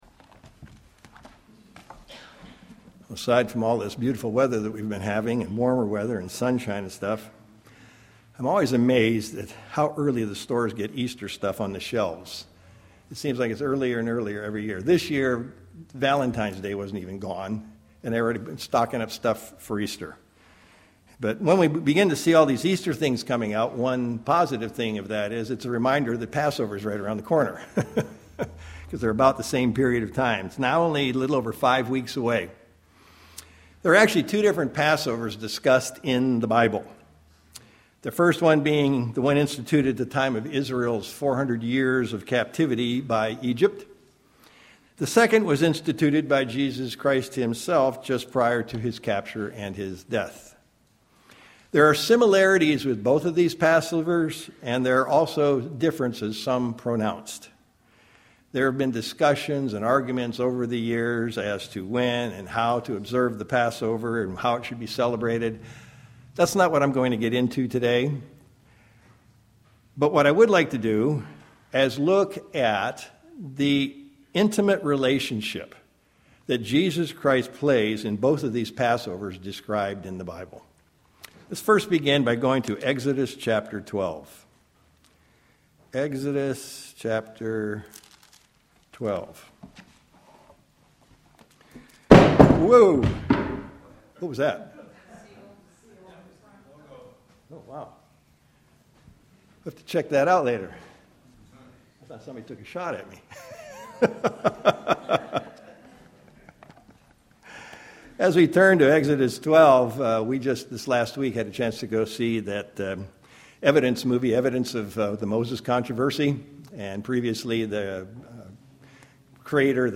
What I'd like to do today in this sermon is to look at the intimate relationship that Jesus Christ has with both of the Pass overs discussed in the Bible as well as with each and everyone of us! Jesus Christ truly is our Passover Lamb sacrificed for us and and the sins of the world!
Given in Sacramento, CA